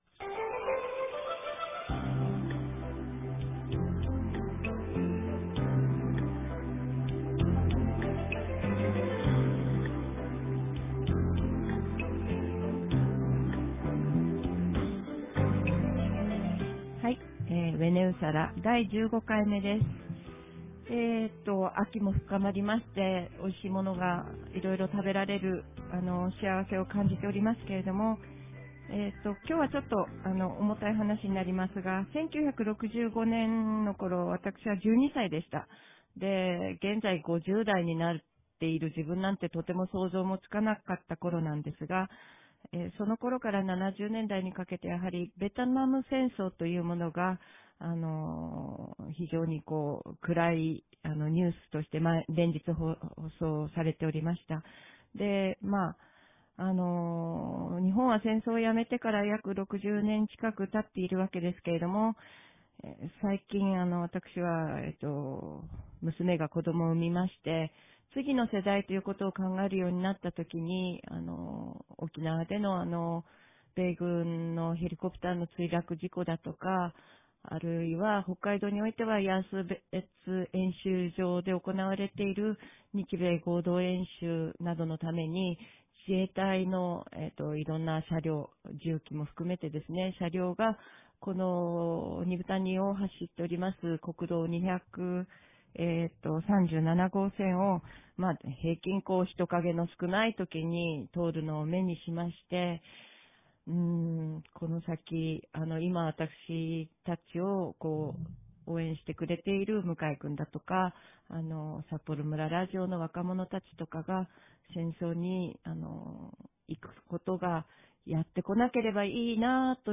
■スタッフ挨拶